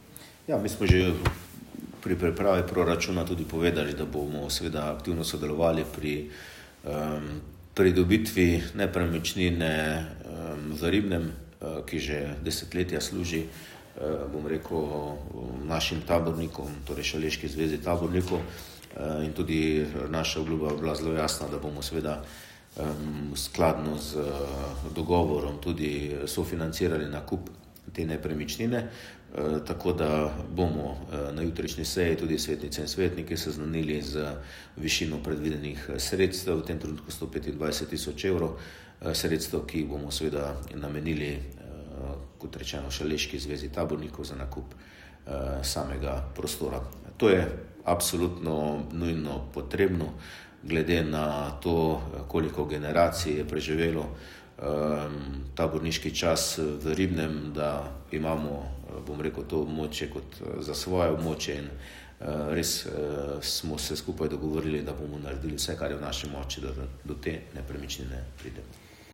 izjavo župana Mestne občine Velenje Petra Dermola.